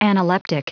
Prononciation du mot analeptic en anglais (fichier audio)
Prononciation du mot : analeptic